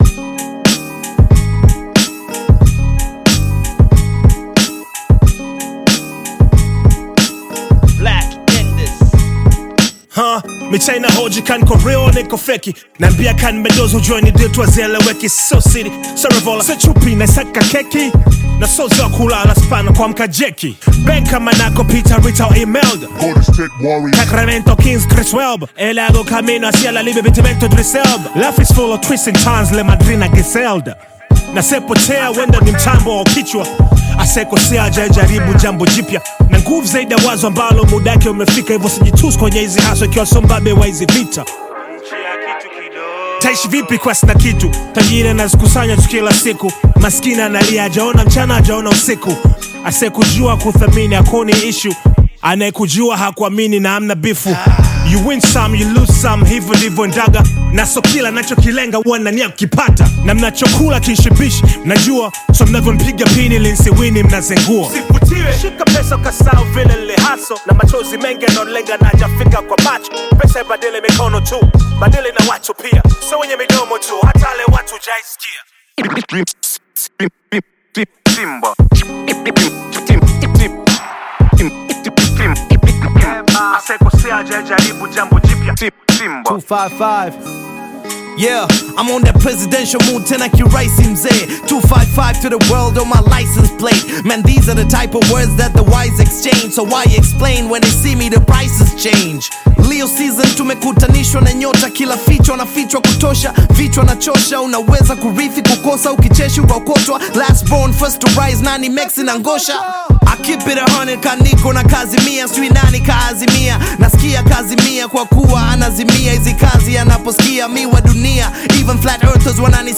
Bongo Flava
Bongo Flava You may also like